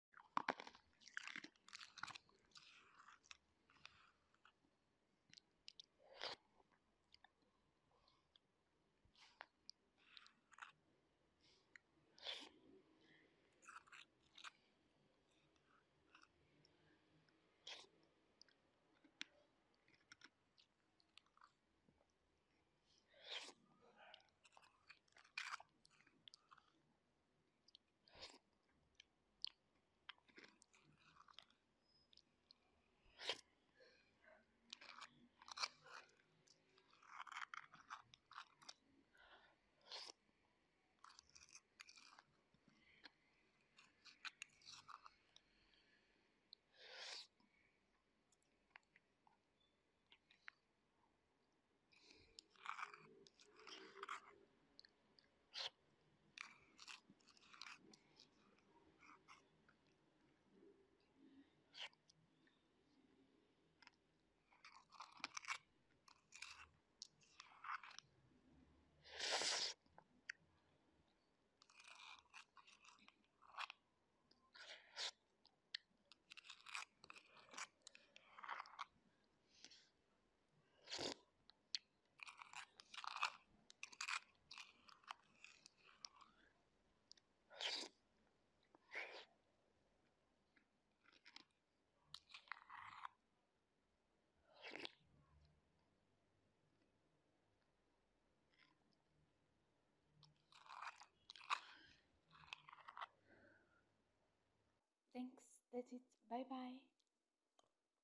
Mango-flavored Shaved Ice 🧊🥶 - Mukbang Food ASMR😋😋 - Part 6